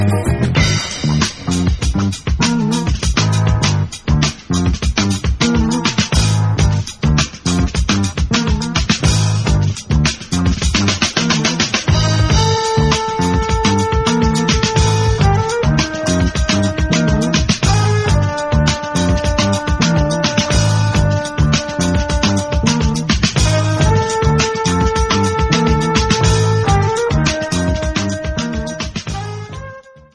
Funk
Hip Hop
Jazz